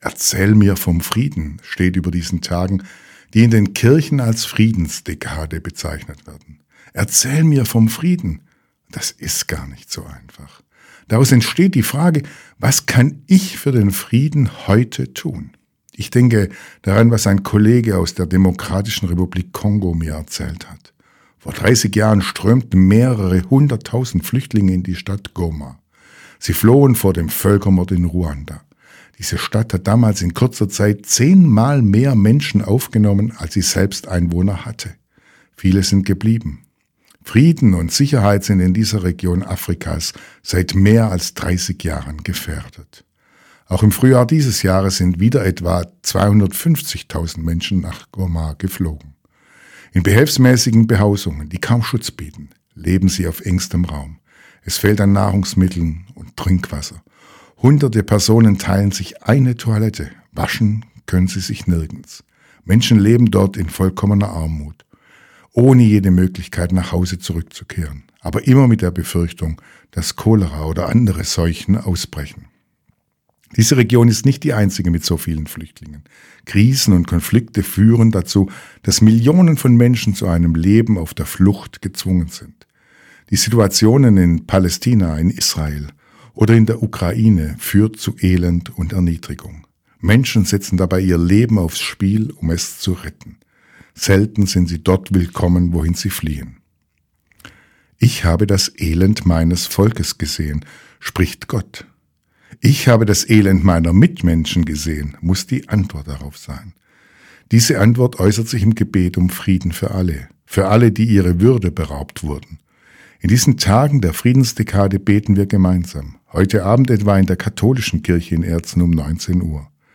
Radioandacht vom 14. November